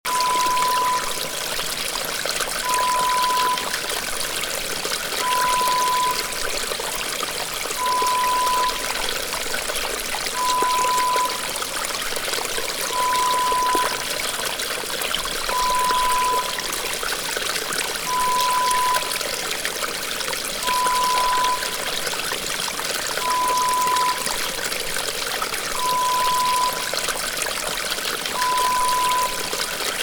Mountain Stream - Babbling Brook - Water 07.Wav Sound Effect
(0:59)MOUNTAIN STREAM, BABBLING BROOK, WATER: This true-stereo water sound effect is delivered instantly in CD quality WAV format (preview contains a security watermark tone).
PREVIEW = Lo-Fi mp3 with pink tone security watermark (beep).
Babblingbrook07_60sec_SoundeffectSAMPLE.mp3